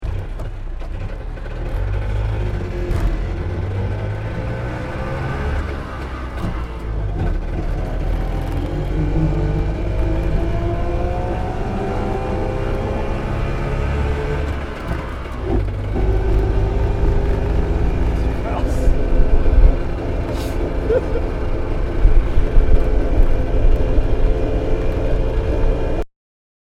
Motorsounds und Tonaufnahmen zu Messerschmitt Fahrzeugen (zufällige Auswahl)
Messerschmitt_KR_200_Fahrgeraeusch.mp3